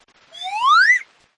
Tesla Lock Sound Cartoon – Flute Up
Cartoon Flute Up sound
(This is a lofi preview version. The downloadable version will be in full quality)
JM_Tesla_Lock-Sound_Cartoon-Flute-Up_Watermark.mp3